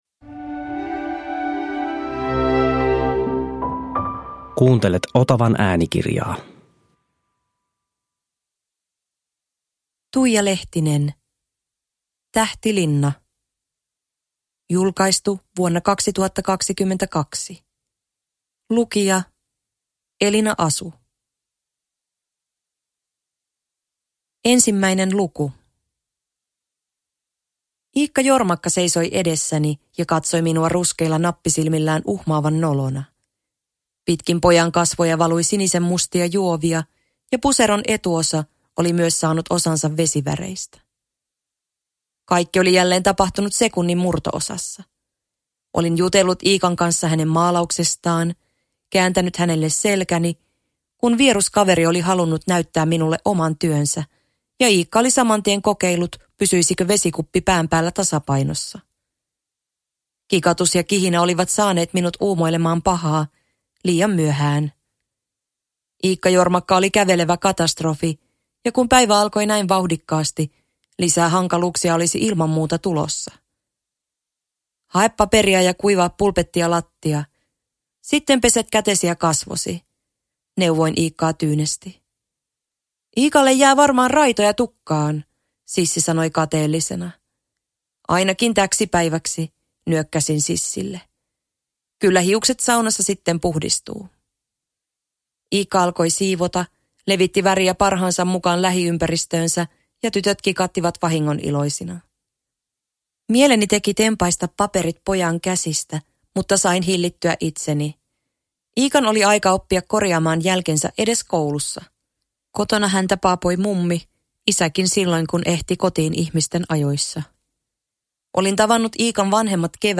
Tähtilinna – Ljudbok – Laddas ner